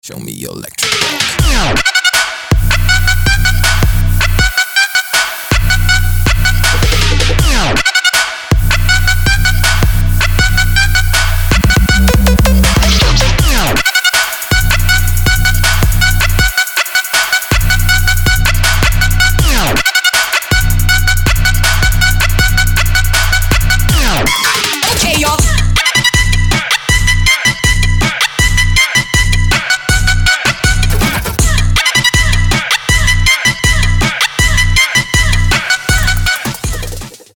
• Качество: 224, Stereo
Trap
club
electro
трэп